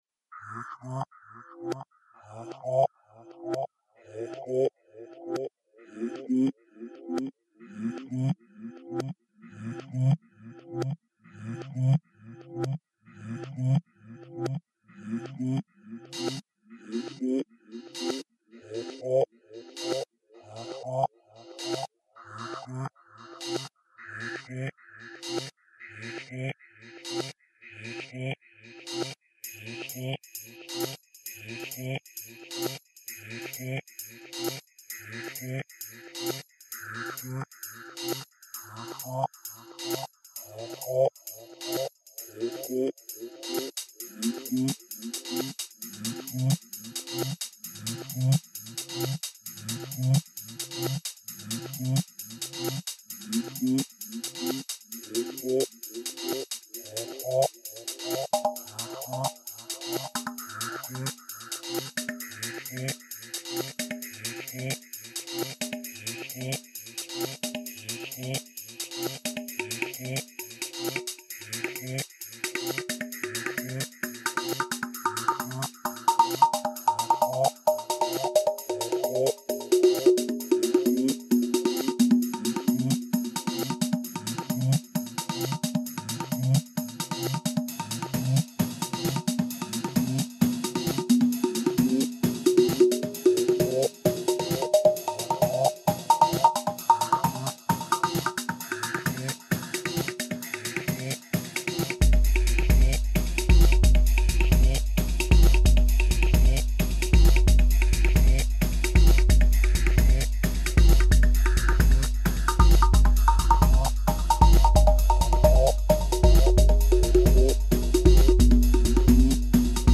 Recorded: June 2001 | Genre: Tech/Progressive House